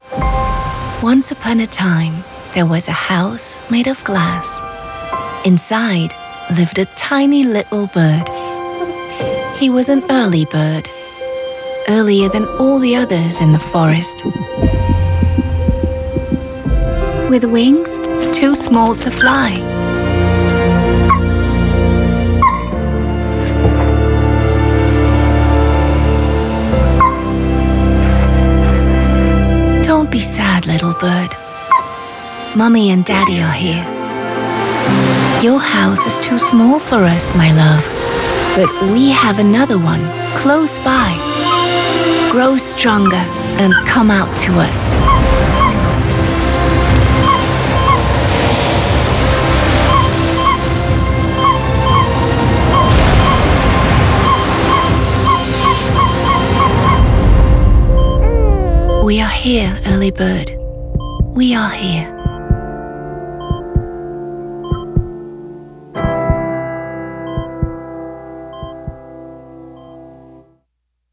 sehr variabel, markant
Off, Commercial (Werbung)